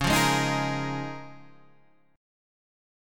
C#6add9 chord